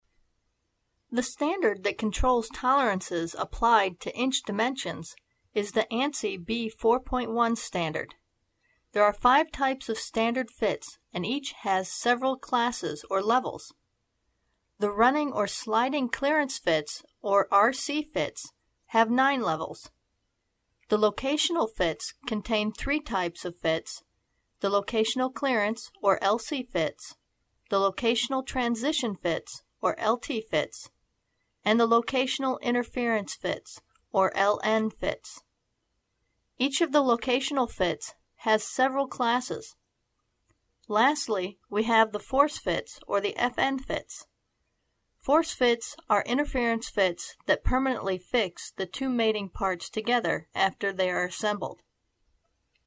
Lecture content